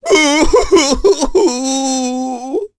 Ezekiel-vox-Sad.wav